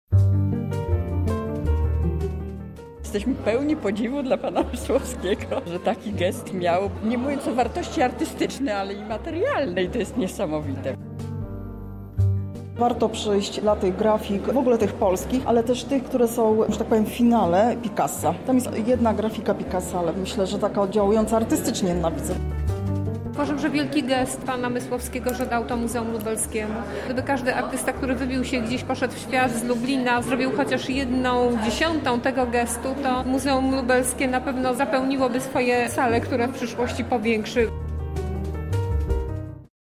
Uczestnicy wernisażu byli pod wrażeniem kolekcji przekazanej przez artystę.